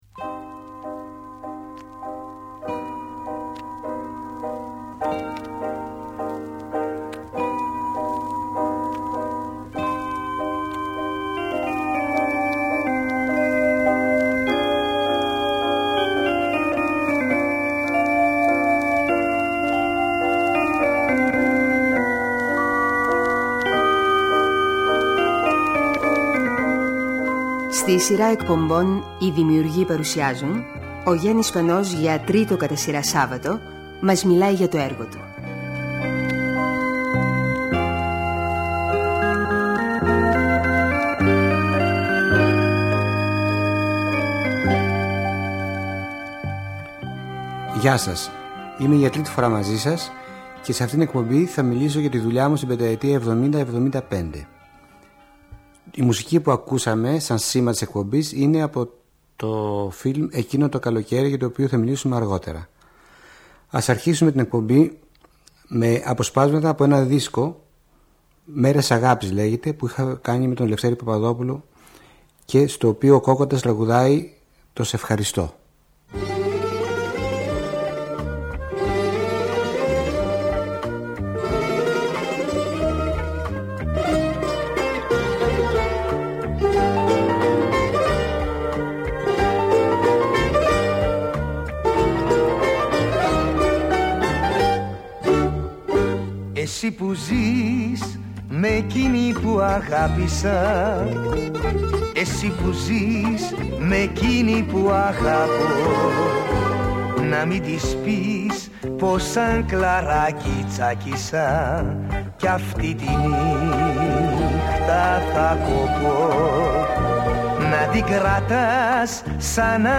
Είναι ένα από τα σπάνια ντοκουμέντα του Αρχείου της ΕΡΑ, όπου ακούμε τον Γιάννη Σπανό να αφηγείται τη ζωή του.